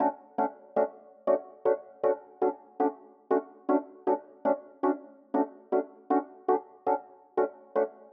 12 ElPiano PT1.wav